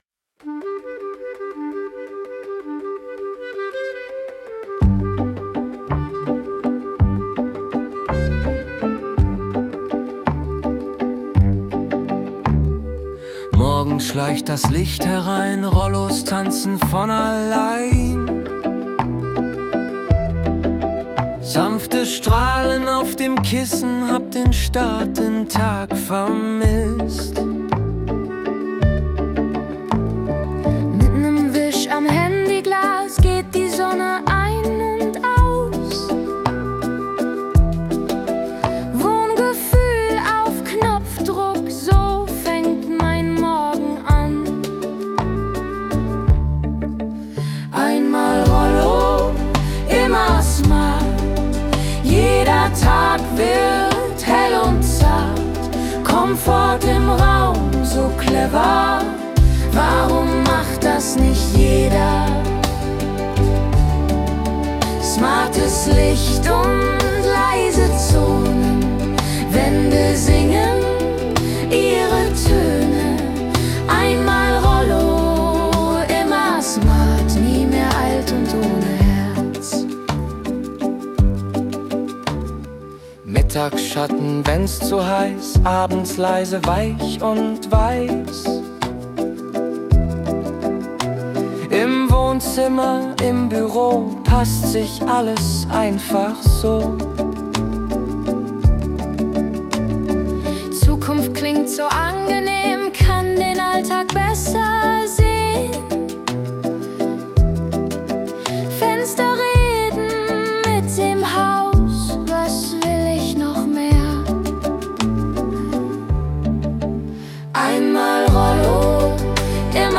Song zum Blogthema